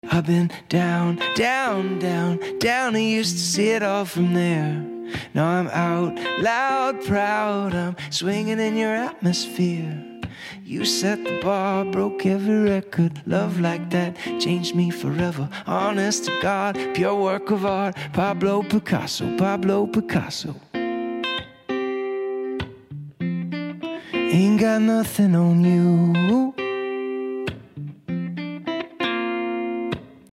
in the live room.